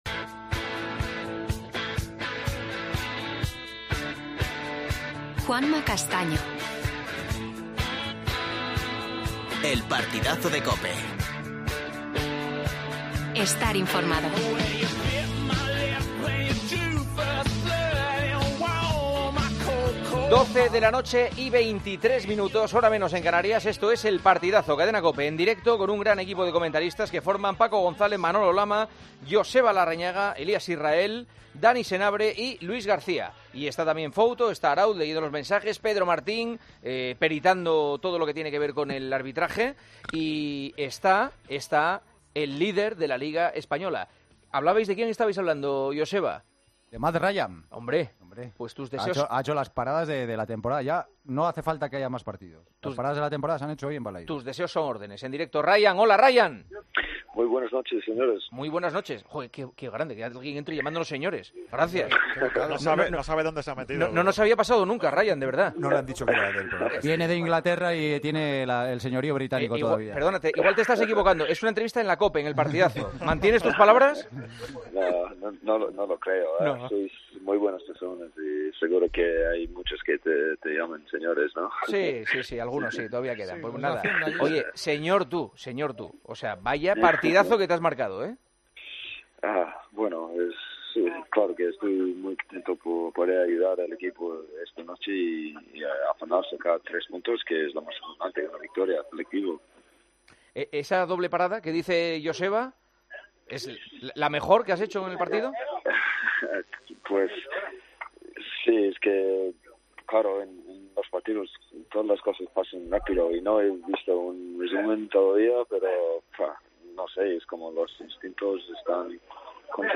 AUDIO: La Real Sociedad, líder de Primera tras ganar al Celta. Entrevista a Ryan. Hablamos con Lluis Canut del despido de Koeman como entrenador del Barça.